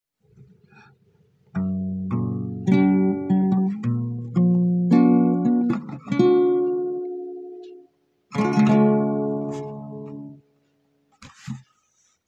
• Flat-to-Flat: A flush connection indicates a Consonant interval (a 3rd or a 5th.)
Consonant Path (Flat-to-Flat):